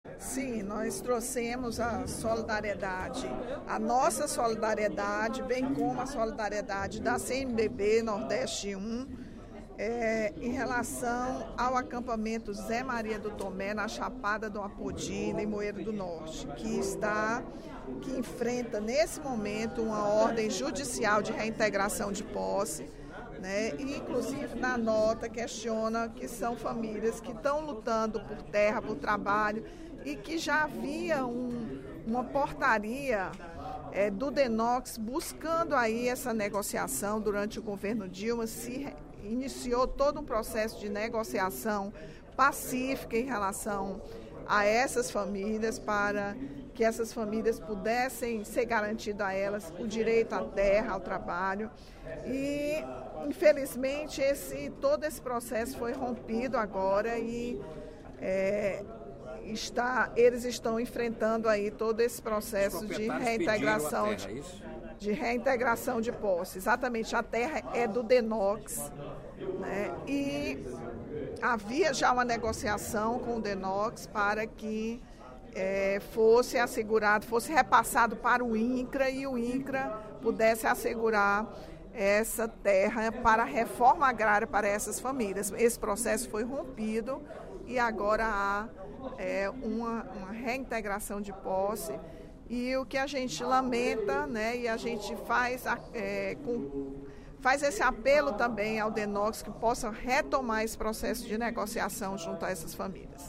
A deputada Rachel Marques (PT) lamentou, durante o primeiro expediente da sessão plenária desta terça-feira (30/05), o pedido de reintegração do terreno ocupado pelo acampamento Zé Maria do Tomé, localizado na Chapada do Apodi, em Limoeiro do Norte.